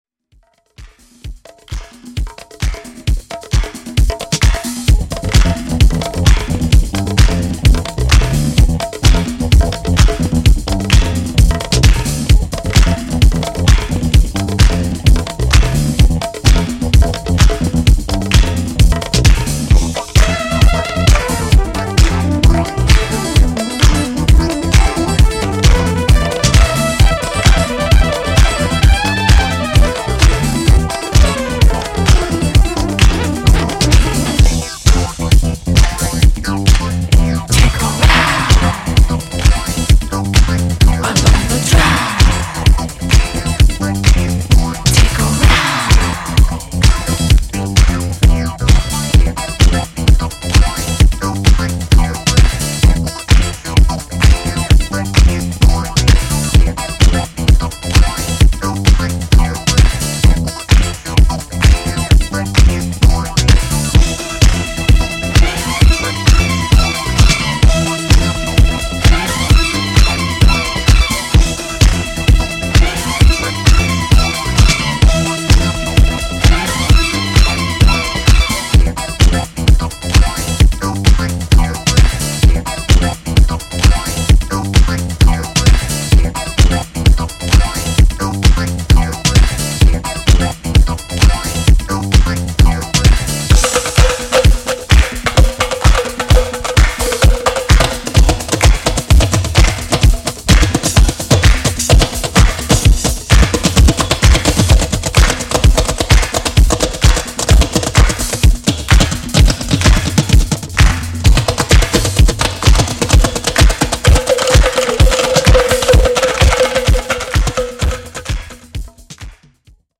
built for the dance floor.
Disco